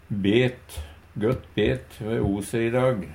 Tilleggsopplysningar Kan òg verte uttala "bett".